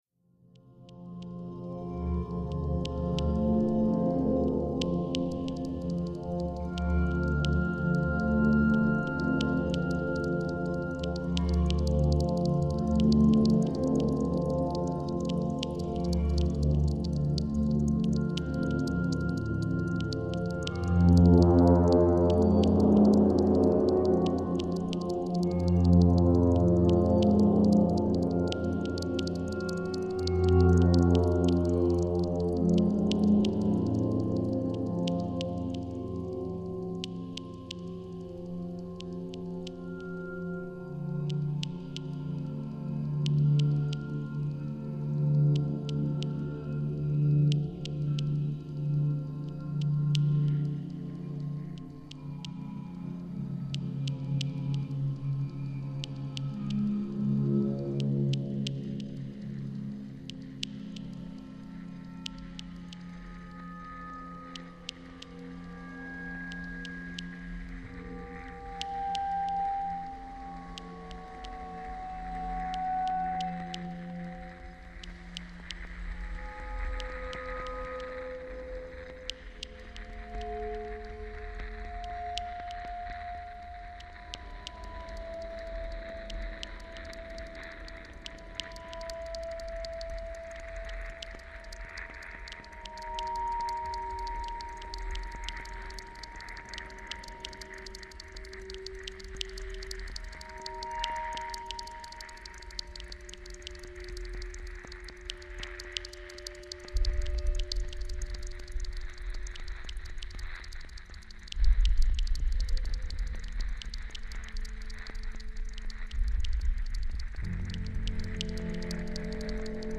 Stahl elektrisch